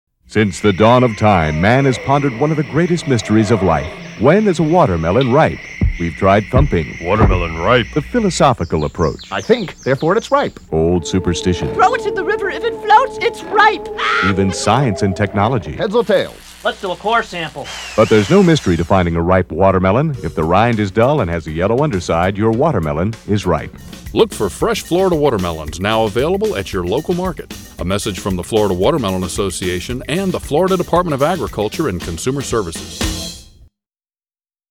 Florida_Watermelon_Radio_Spot_1.mp3